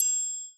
Percs
Dy Trap Triangle (1).wav